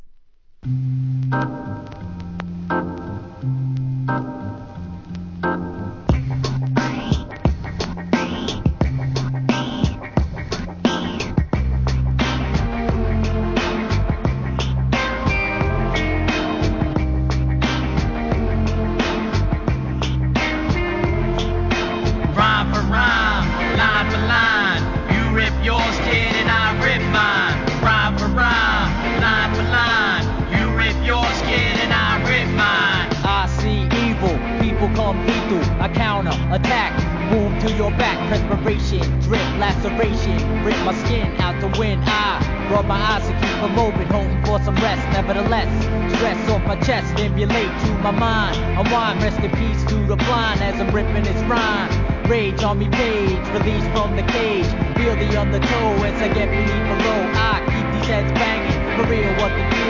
HIP HOP/R&B
2000年、BROOKLYNアンダーグランド!!